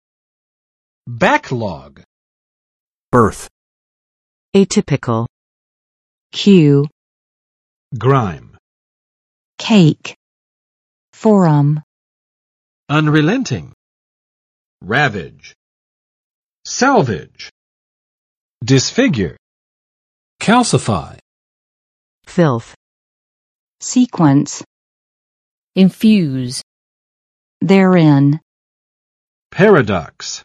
[ˋbæk͵lɔg] n. 积压